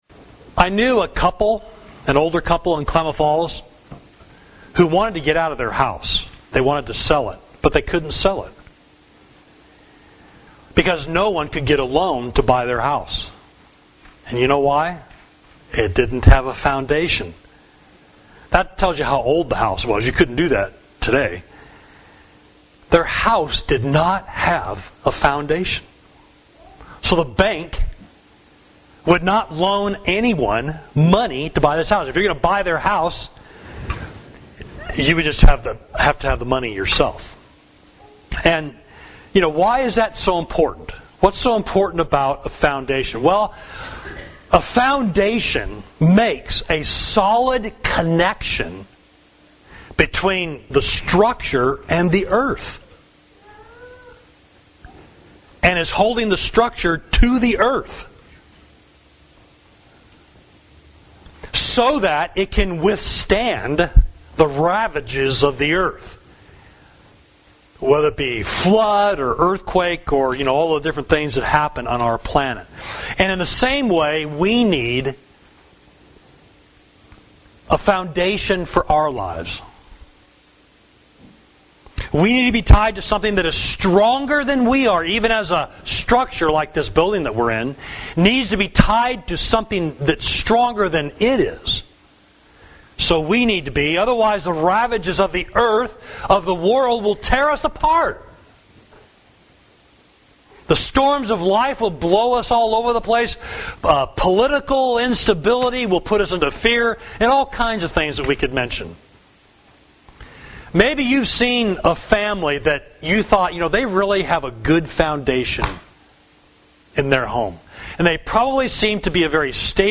Sermon: What Is the Foundation of Your Life?